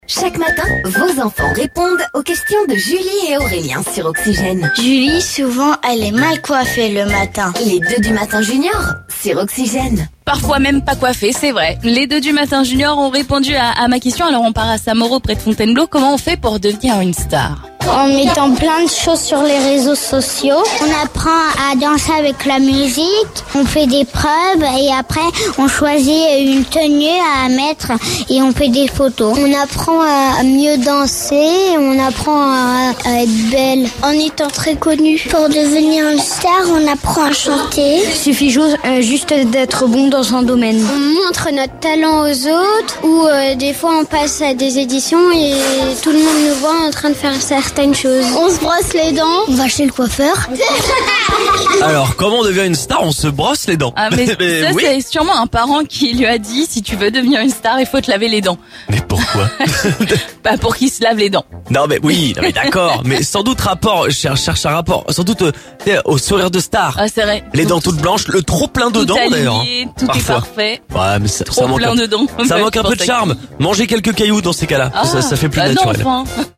Ecoutons les conseils des enfants Seine-et-Marnais sur comment devenir une star...